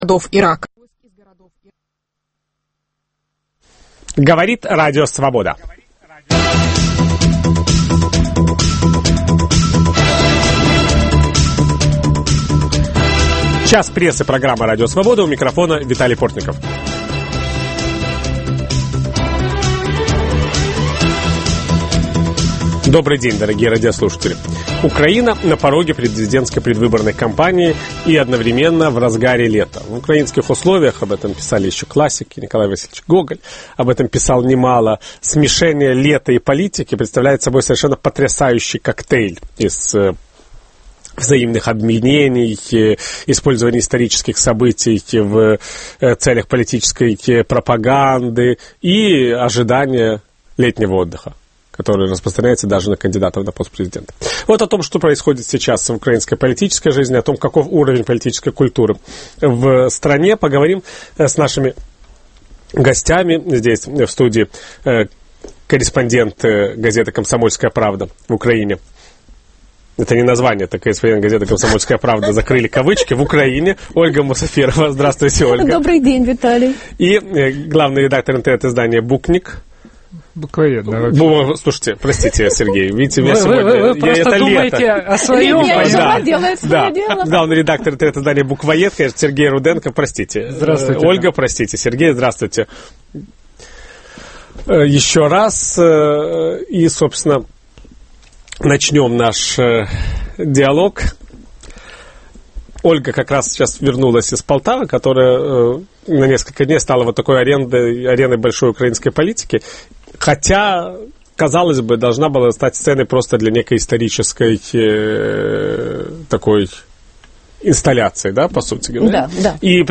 Украинские политики обвиняют друг друга в уголовных преступлениях. До какого уровня может дойти конфронтация в стране накануне президентских выборов? Ведущий беседует с украинскими журналистами